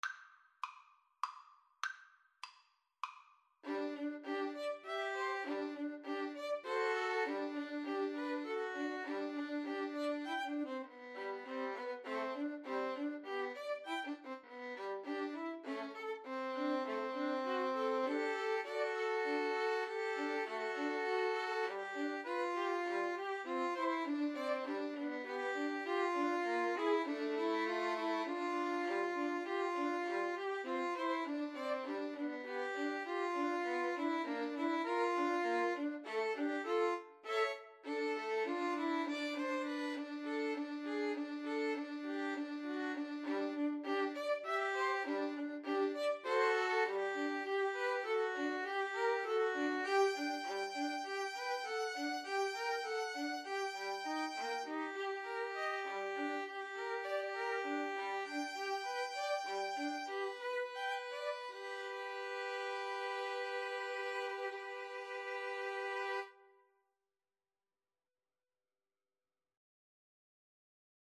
G major (Sounding Pitch) (View more G major Music for Violin Trio )
3/4 (View more 3/4 Music)
~ = 100 Allegretto grazioso (quasi Andantino) (View more music marked Andantino)
Violin Trio  (View more Intermediate Violin Trio Music)
Classical (View more Classical Violin Trio Music)